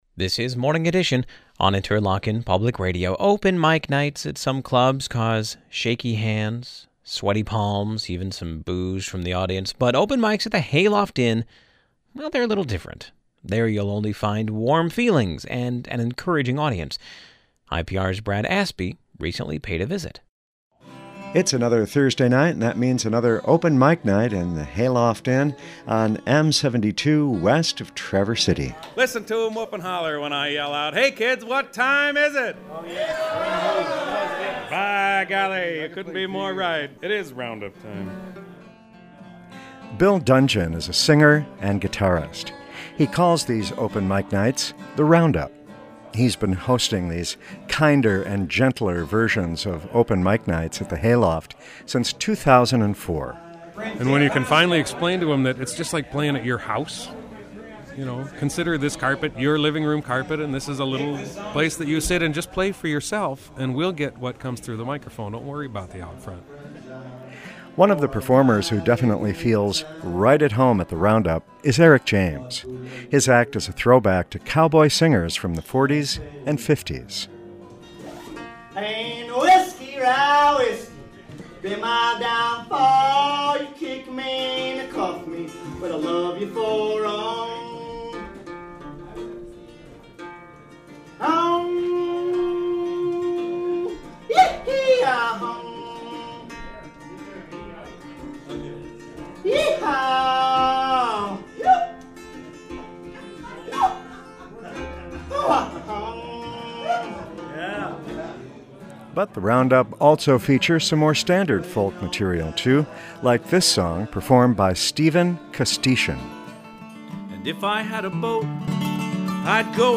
• Открытый микрофон в отеле Hayloft Inn
Ночи с открытым микрофоном в некоторых клубах вызывают дрожь в руках, потные ладони и даже некоторое освистывание публики. Но теплые чувства и воодушевляющая публика встретится только по четвергам в гостинице «Сейлофт» на шоссе М-72, к западу от Траверс-Сити.
«The Roundup» также содержит более стандартный фолк-материал.
Hayloft_Inn_0.mp3